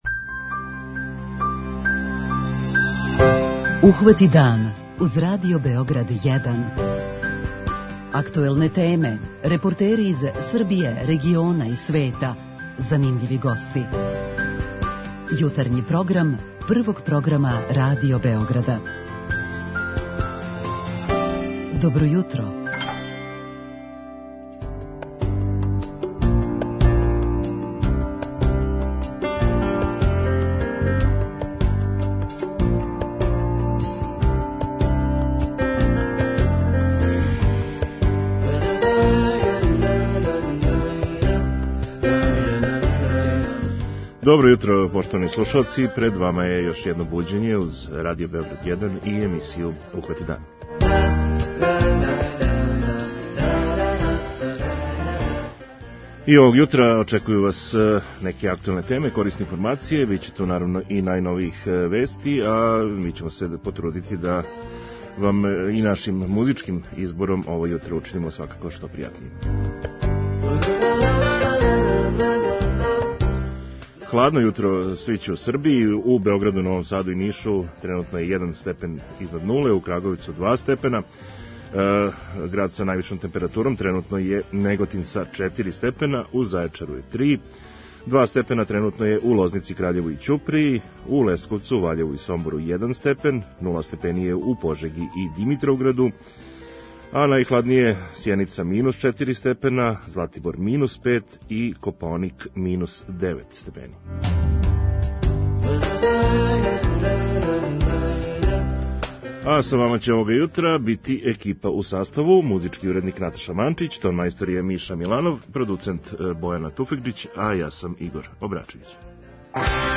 Јутарњи програм Радио Београда 1!